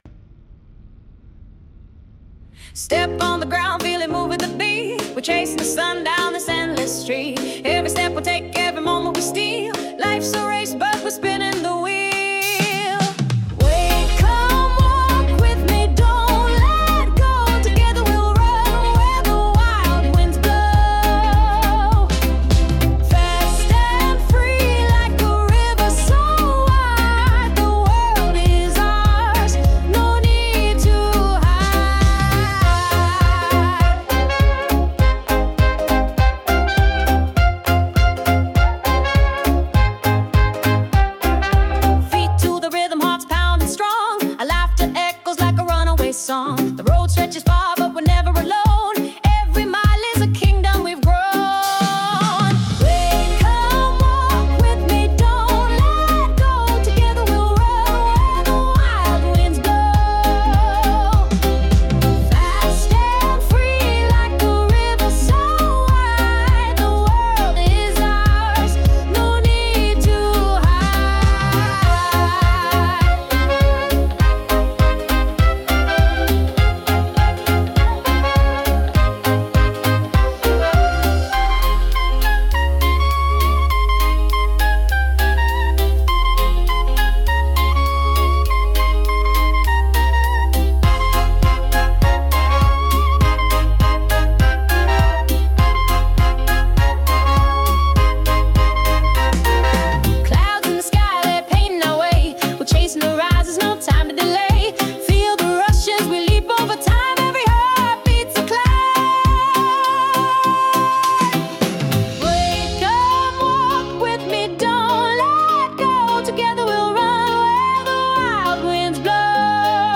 With Vocals / 歌あり
🎺💃🌟 Energetic yet graceful.
ノリの良いエレクトロスウィングに、柔らかく漂う女性コーラスが重なる、 女性らしさもかっこよさも引き出せる歌入りの一曲！